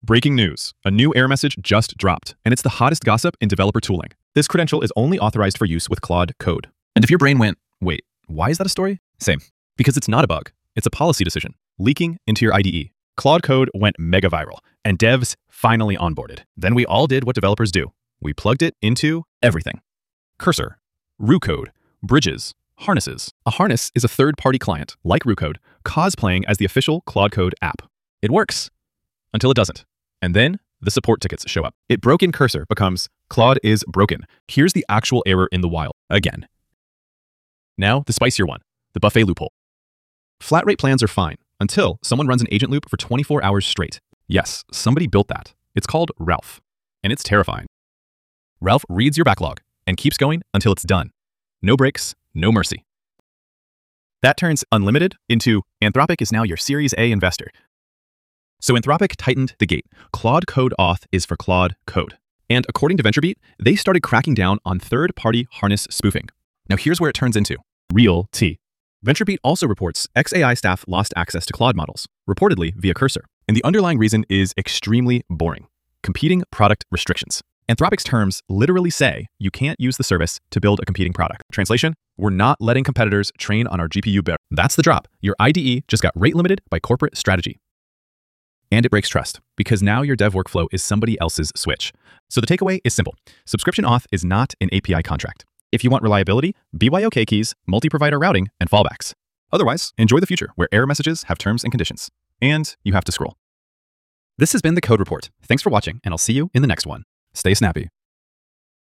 Voiceover
The overall pacing is energetic and mostly on-brand for Fireship, but there are several segments that are rushed to the point of losing punchline impact, and a handful of micro-segments that feel awkwardly clipped or draggy. The fastest segments blow past 300 WPM, making them hard to follow and robbing key moments of their comedic or dramatic landing. Meanwhile, some ultra-short or slow segments break the rhythm, feeling either like afterthoughts or unintentional pauses.